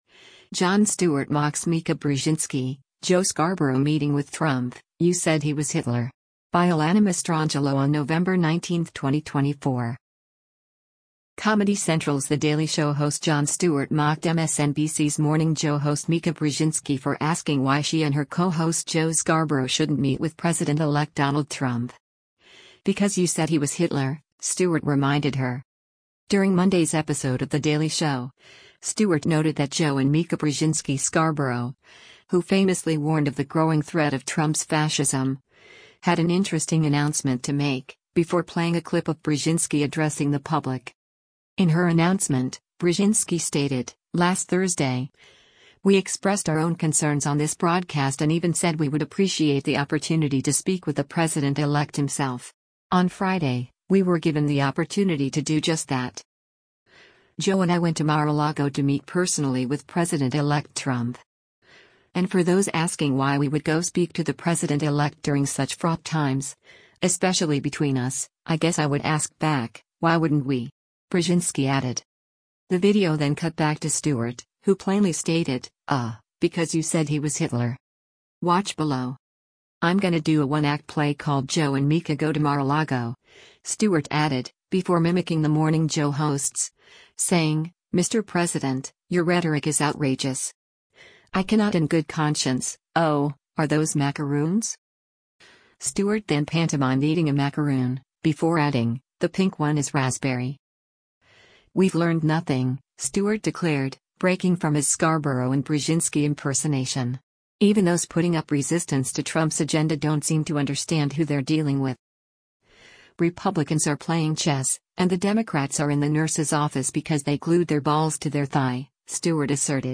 During Monday’s episode of The Daily Show, Stewart noted that “Joe and Mika Brzezinski Scarborough, who famously warned of the growing threat of Trump’s fascism,” had “an interesting announcement to make,” before playing a clip of Brzezinski addressing the public.
“I’m gonna do a one-act play called ‘Joe and Mika go to Mar-a-Lago,'” Stewart added, before mimicking the Morning Joe hosts, saying, “Mr. President, your rhetoric is outrageous! I cannot in good conscience — oh, are those macaroons?”